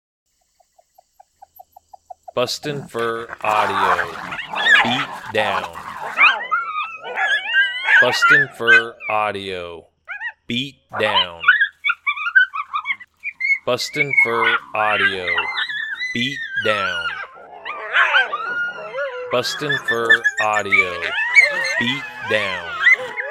Juvenile male and female coyote fighting over food with other pack members mixing in some barking and howling. Very aggressive vocals in this sound.
• Product Code: pups and fights